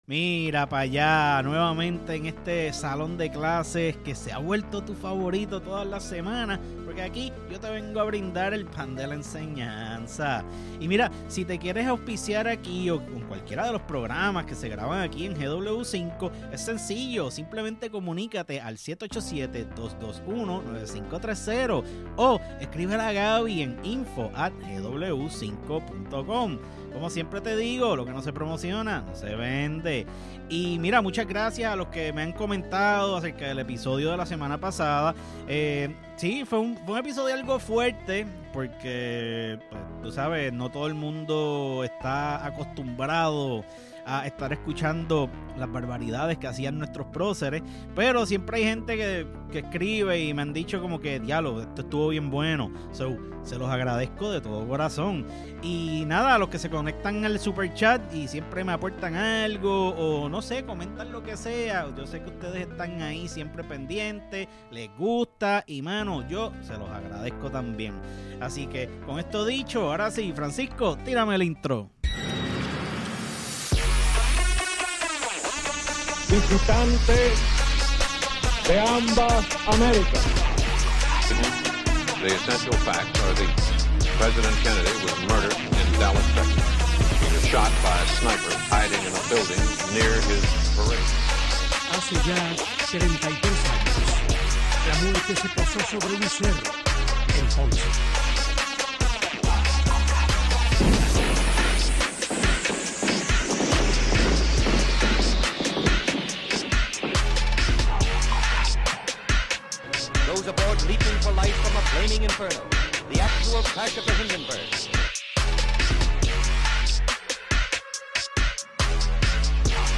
Grabado en GW Cinco Studios y es parte del GW5 Network.